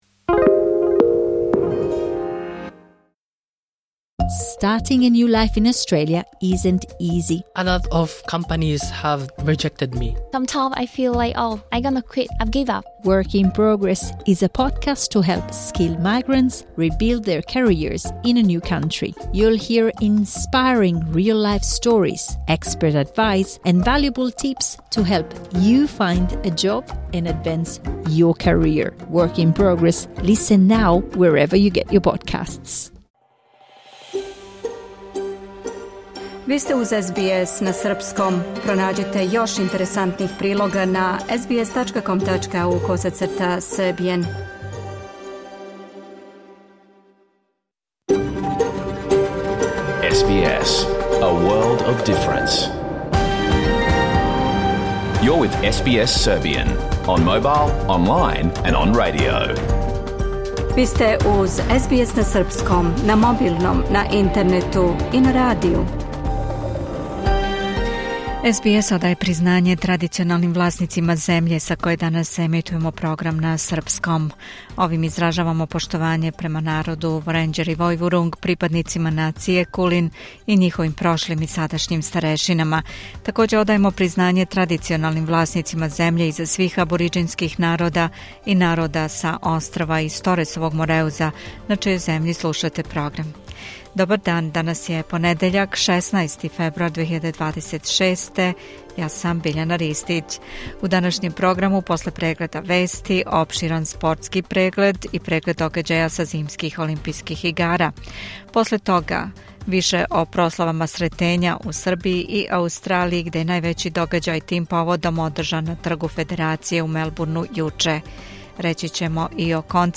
Програм емитован уживо 16. фебруара 2026. године